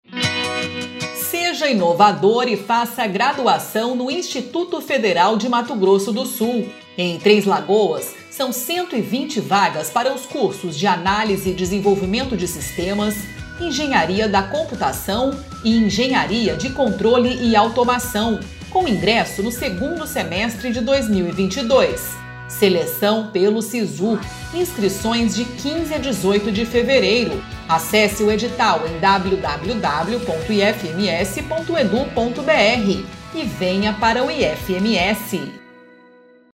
Áudio enviado às rádios para divulgação institucional do IFMS.